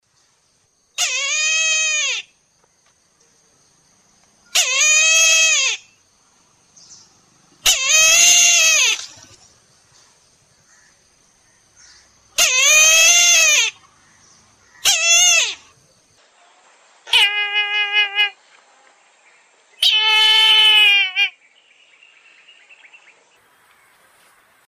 На этой странице собраны разнообразные звуки коршуна – от резких криков до переливчатых трелей.
Звук Браминского коршуна